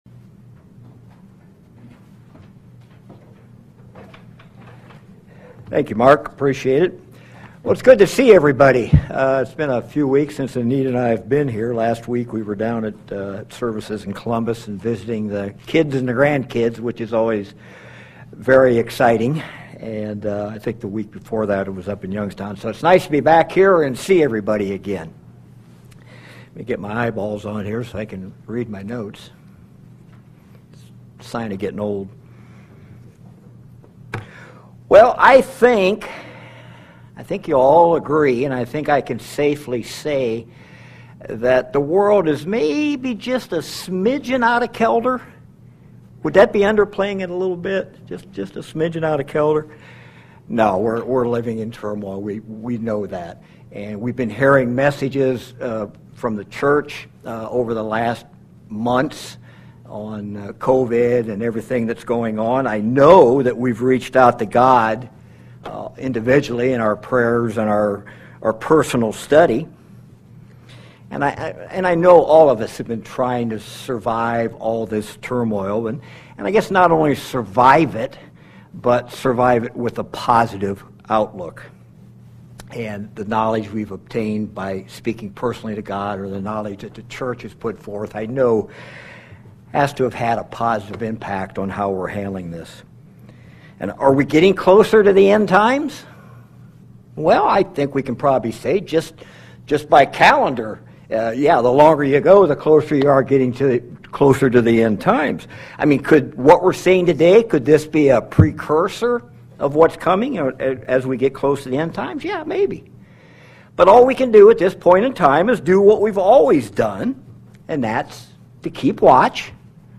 Given in North Canton, OH